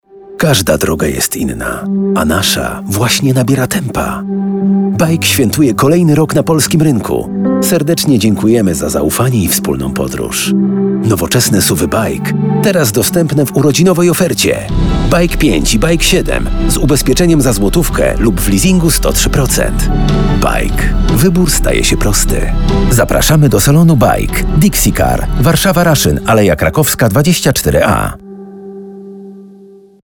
BAIC 7 z Podgrzewanymi Fotelami i Masażem w Standardzie na Wyprzedaży (Reklama)
reklama-baic-urodziny-ubezpieczenie-leasing.mp3